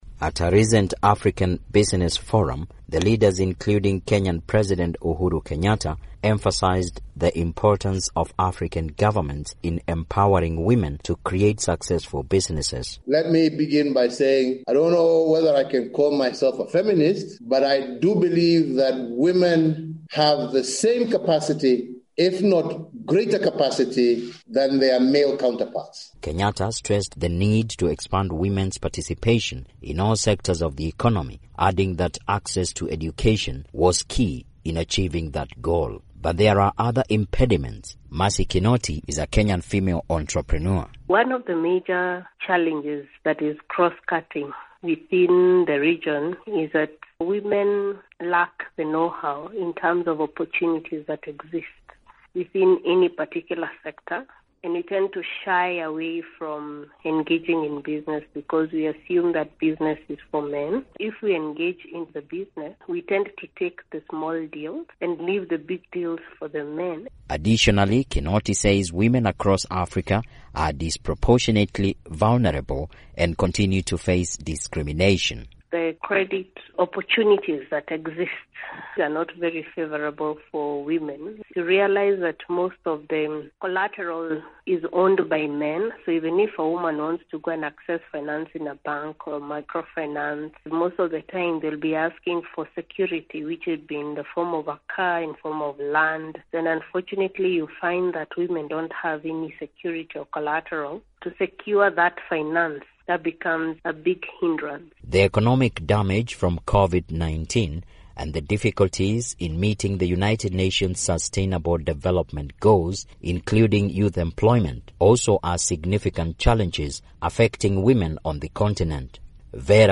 Speaking at the annual Africa Business Forum, African leaders encouraged women to enter the fields of technology and business.